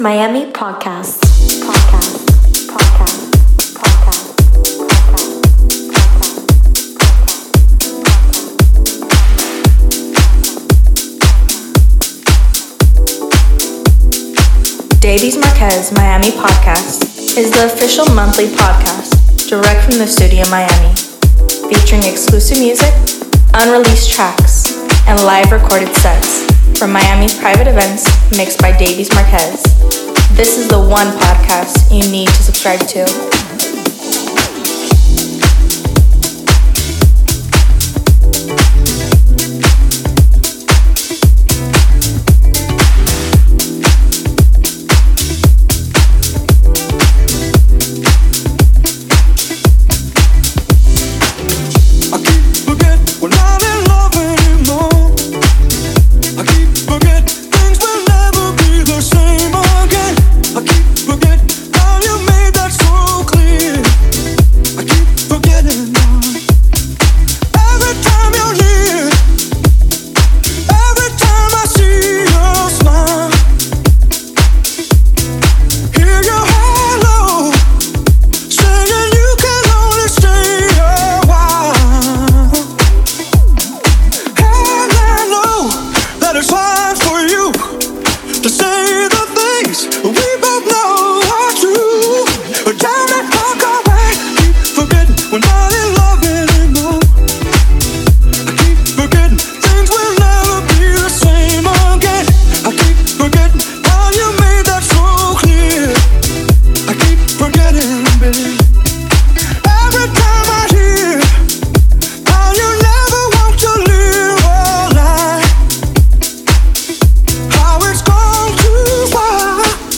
Set Recorded at Orange Blossom May 2025
Deep House
tech house